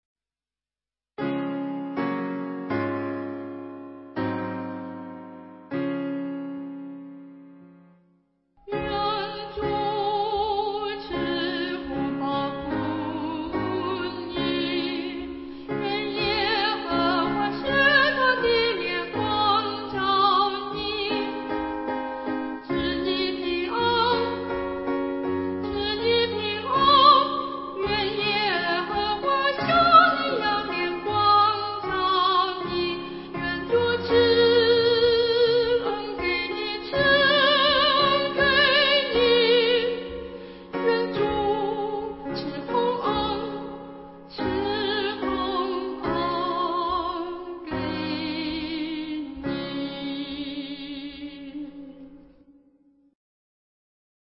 260伴奏